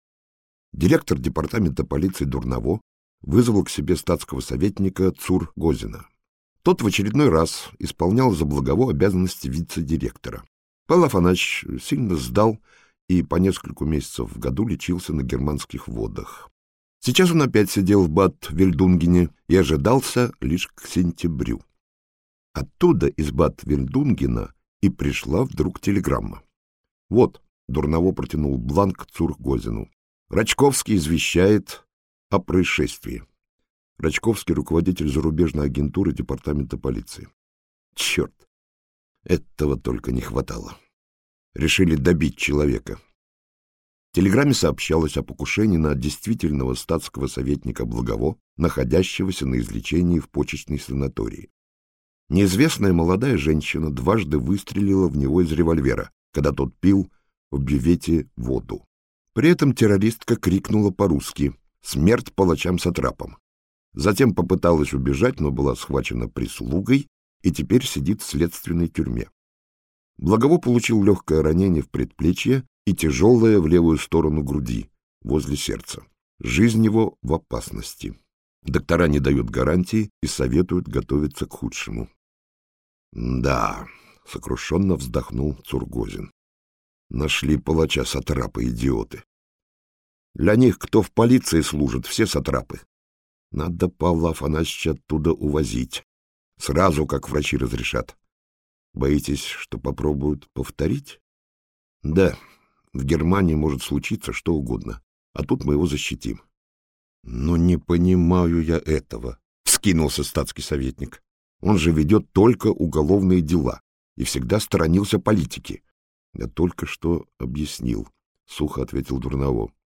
Аудиокнига Варшавские тайны - купить, скачать и слушать онлайн | КнигоПоиск